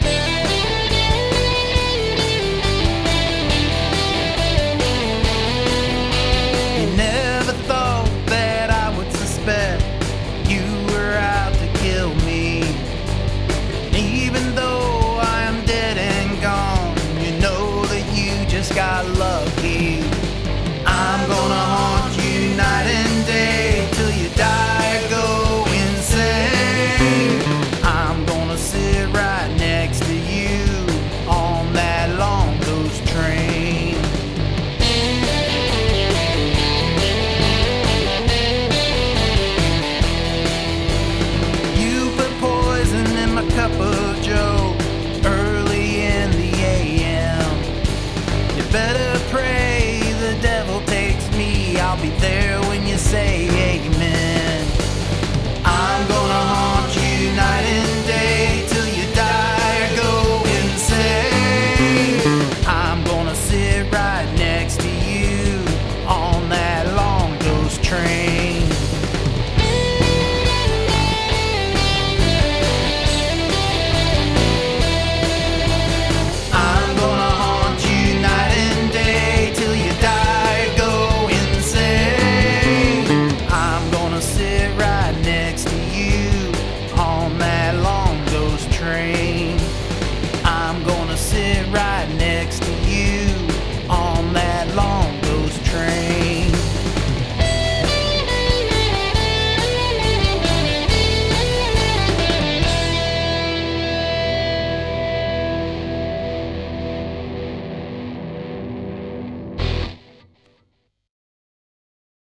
For Alter Ego Fest - I imagined forming an alt country (cowpunk?) band with an electric 12-string as the primary instrument.
The bass is a Harley Benton bass guitar.
The HB-12 sounds fab, and especially liked the spotlighted bass note accent in the right places.
Love hearing people sing from beyond the grave. Cool story, and a raw, energetic performance.
Those guitars sound terrific and stellar vocal work.
There's a really nice octaved 'thickness' coming from that 12-string. It's all performed so well - a nice gritty edge and lots of swagger. The harmonies are fab too.